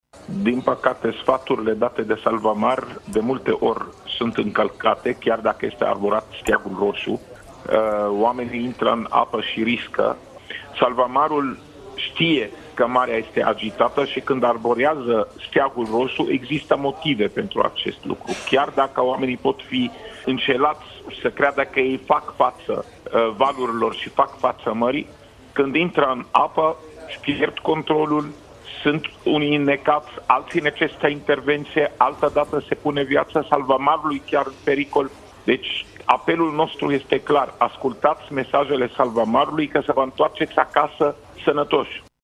Într-o intervenție televizată, la Digi24, șeful DSU, dr. Raed Arafat a reiterat că siguranța trebuie să fie pe primul loc, iar semnalizările salvamarilor trebuie luate în seamă: